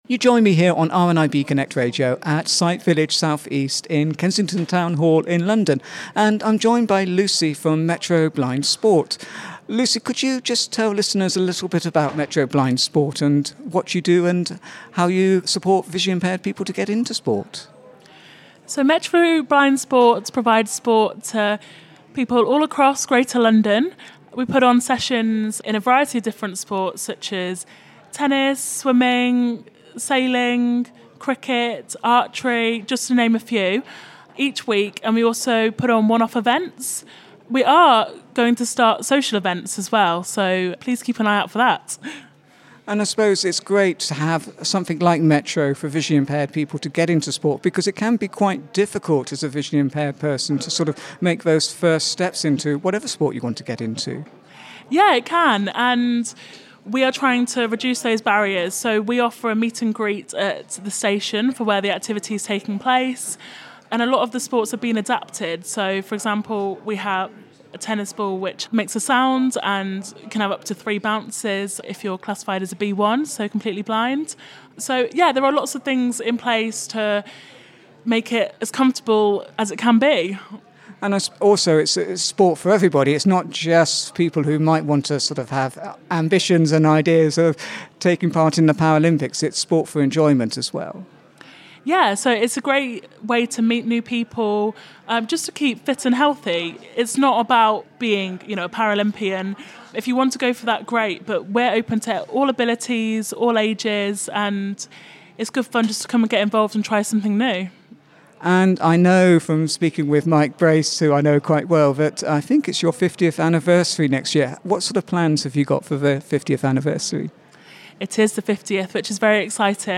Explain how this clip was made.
The annual exhibition of technology and services for blind and partially sighted people, Sight Village South East was back at Kensington Town Hall in London on Tuesday 8 November 2022